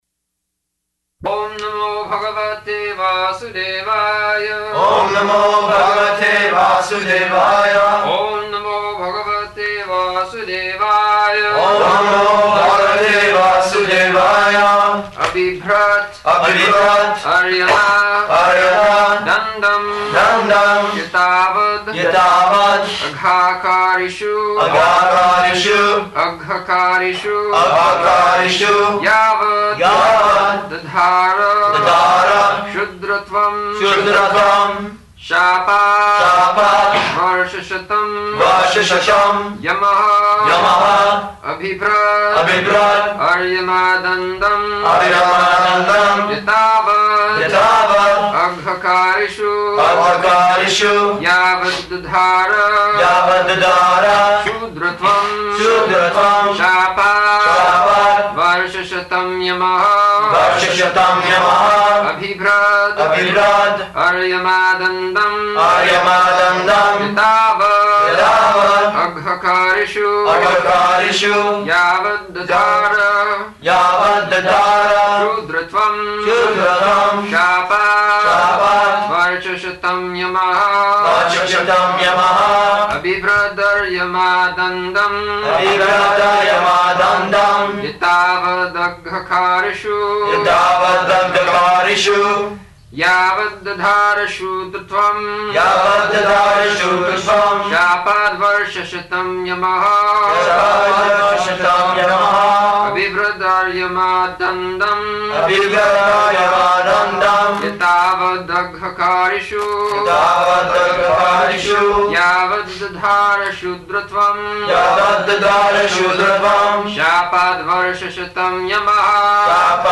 -- Type: Srimad-Bhagavatam Dated: June 4th 1974 Location: Geneva Audio file